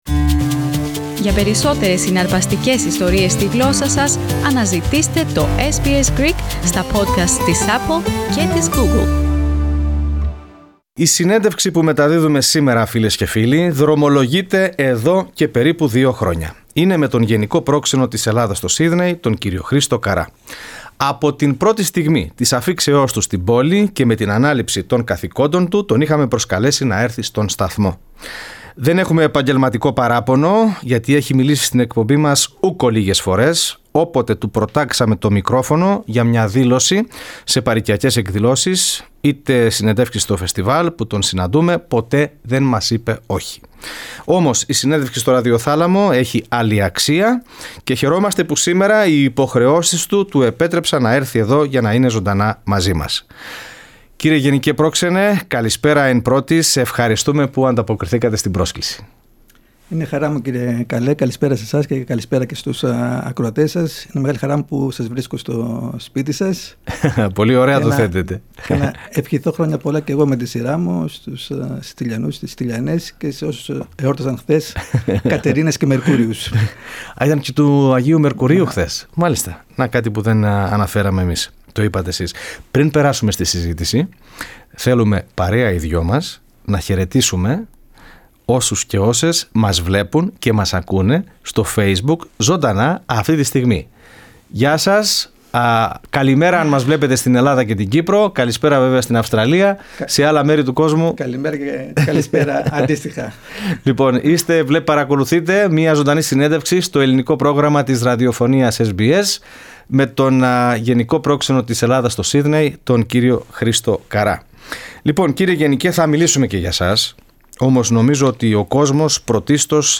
Εκ βαθέων συνέντευξη με τον Γενικό Πρόξενο της Ελλάδας στο Σύδνεϋ κ. Χρήστο Καρρά
Στο στούντιο του Ελληνικού Προγράμματος της Δημόσιας Ραδιοφωνίας SBS, βρέθηκε ο Γενικός Πρόξενος της Ελλάδας στο Σύδνεϋ, κ. Χρήστος Καρράς, μιλώντας για την επικείμενη επίσκεψη του υφυπουργού Εξωτερικών της Ελλάδας, Αντώνη Διαματάρη στο Σύδνεϋ, την επιθυμία του Έλληνα πρωθυπουργού Κυριάκου Μητσοτάκη να βρεθεί στους Αντίποδες, αλλά και για την αγάπη του για την Λογοτεχνία.